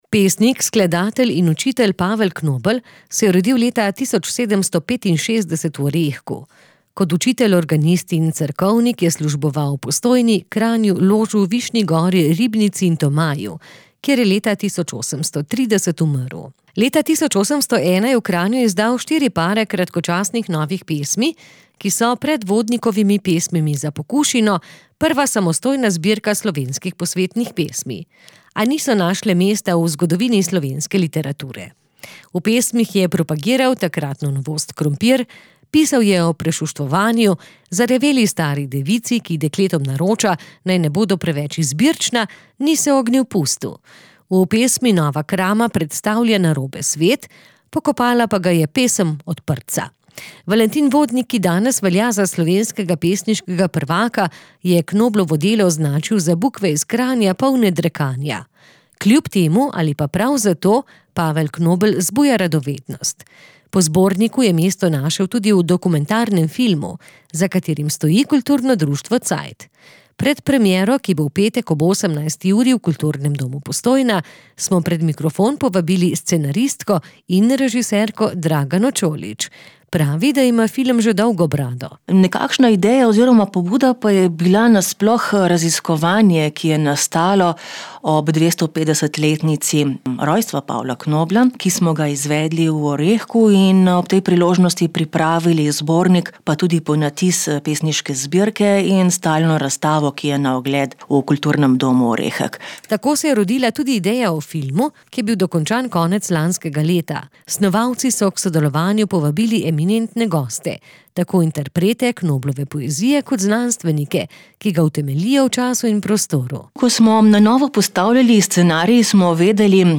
Pred premiero, ki bo v petek ob 18. uri v Kulturnem domu Postojna, smo pred mikrofon povabili